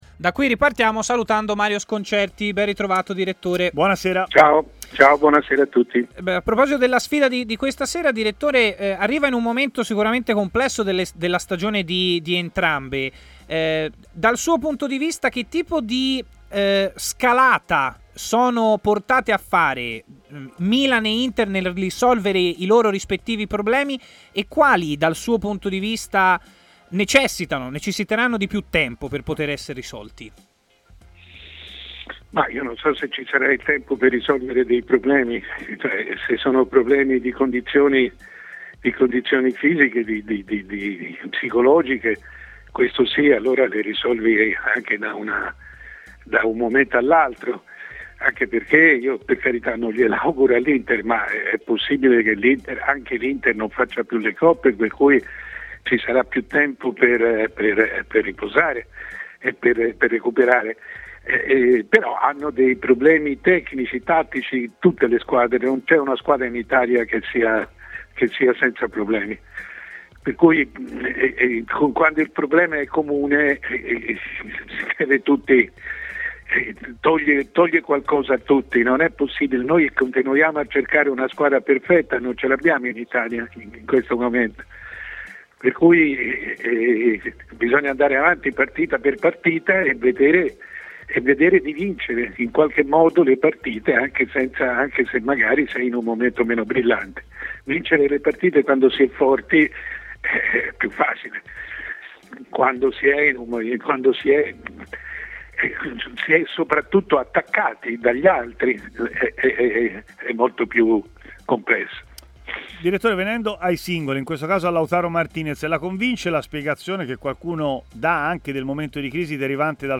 Mario Sconcerti, decano del giornalismo e opinionista di TMW Radio